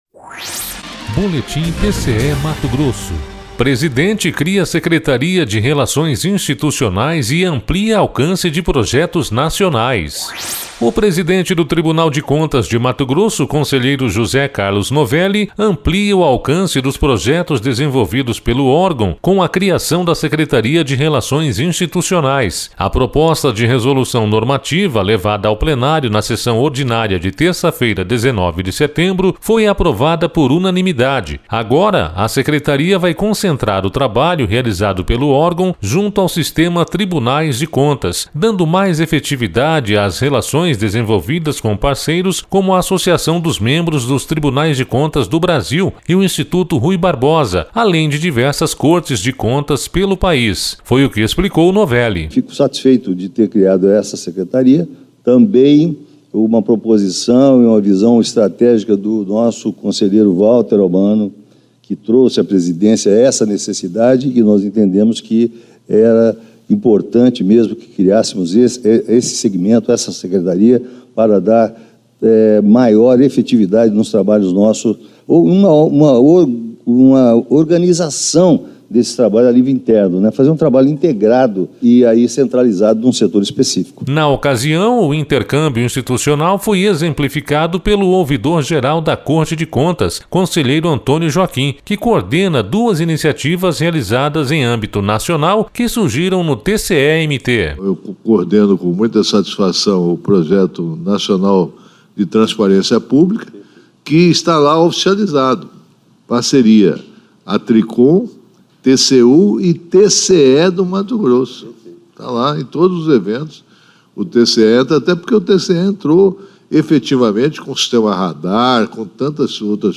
Sonora: José Carlos Novelli – conselheiro presidente do TCE-MT
Sonora: Antonio Joaquim – conselheiro ouvidor-geral do TCE-MT